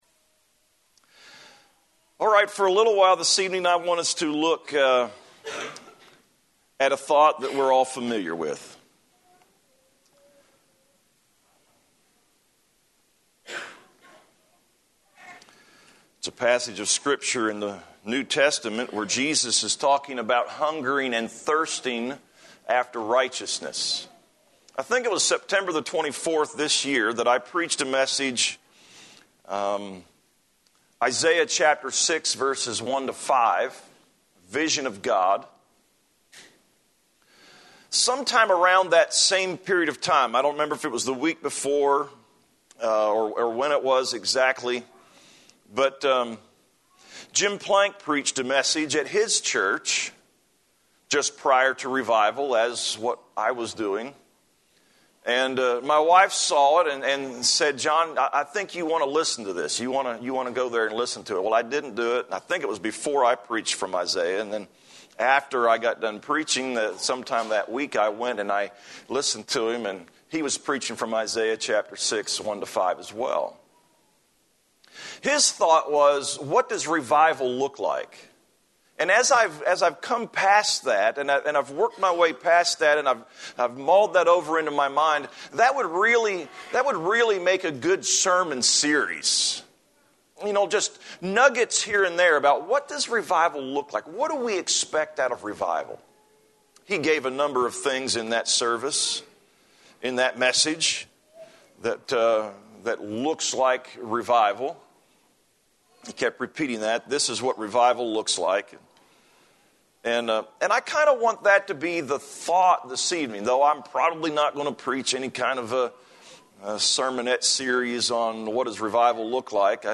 A sermon